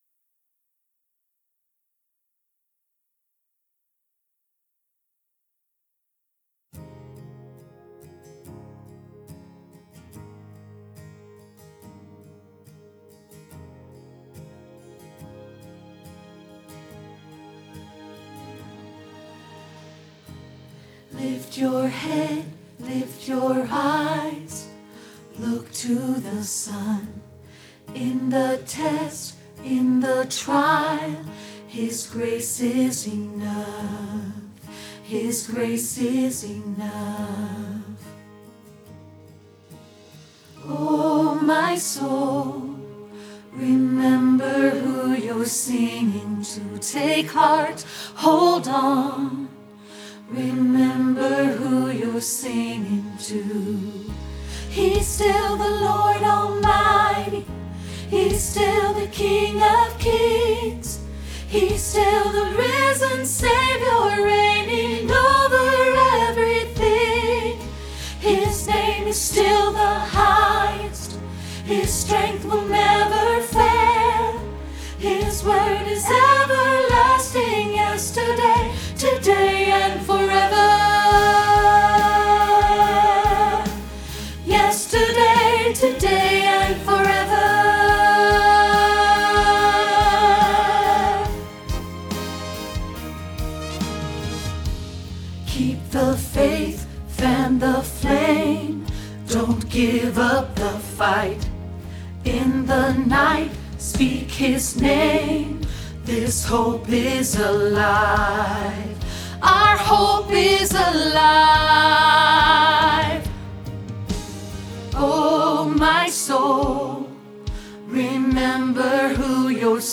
Yesterday, Today, Forever – Alto – Hilltop Choir